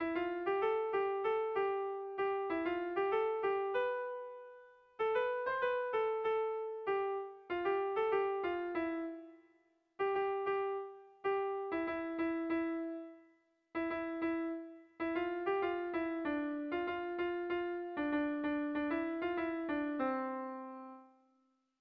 Dantzakoa
AB